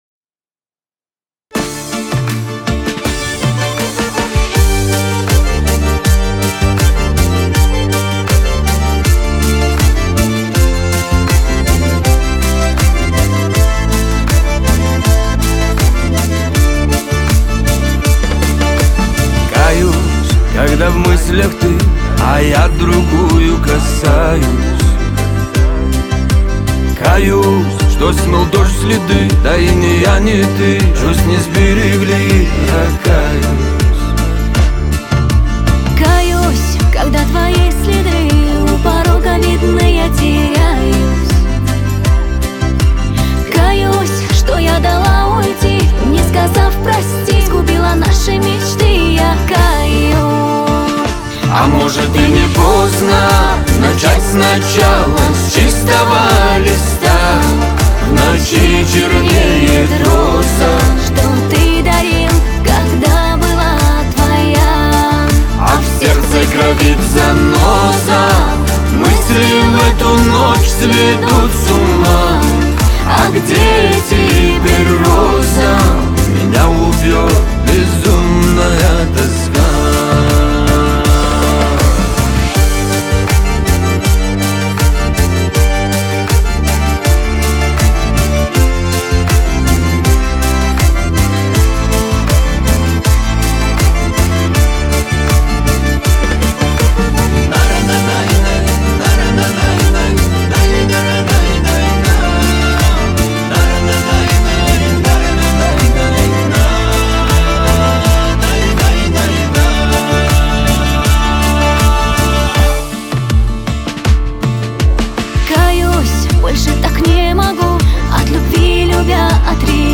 Качество: 320 kbps, stereo
Кавказская музыка